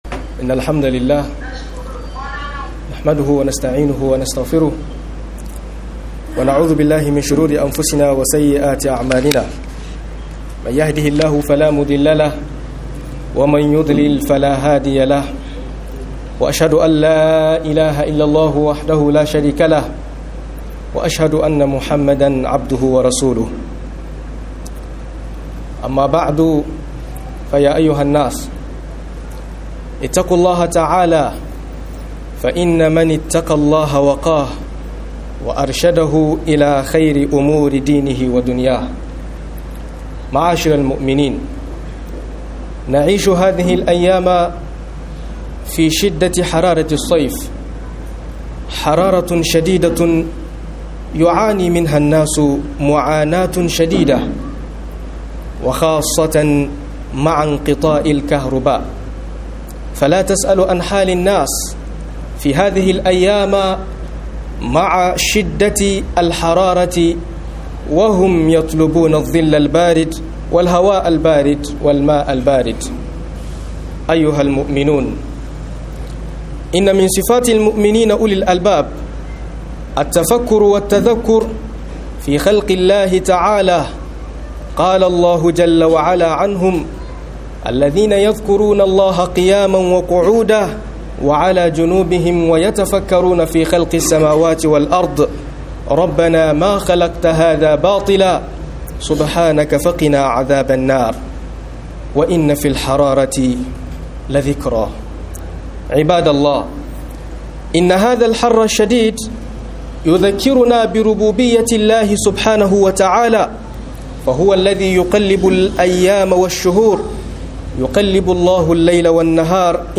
Acikin zafi akoy wa'azi - MUHADARA